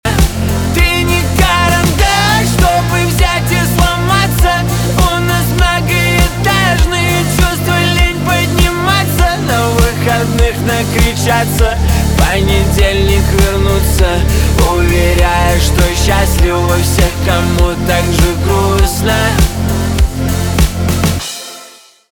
поп
гитара , барабаны , чувственные